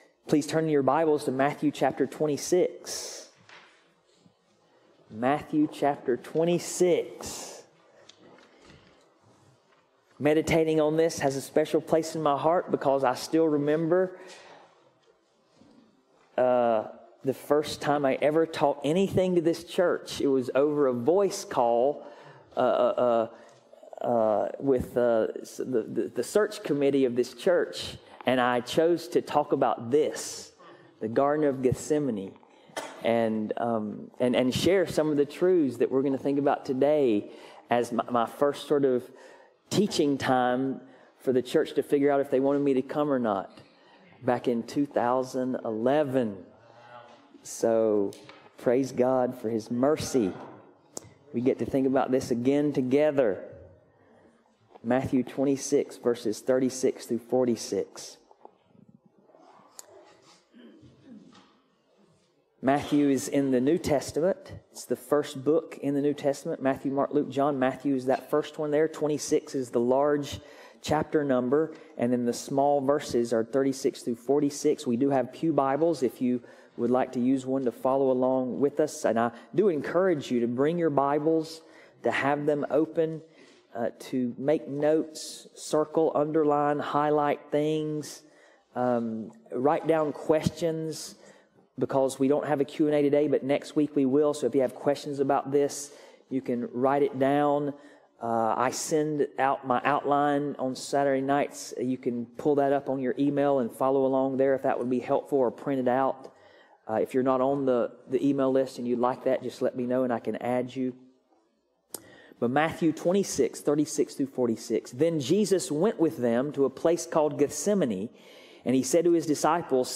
Passage: Matthew 26:36-46 Service Type: Sunday Morning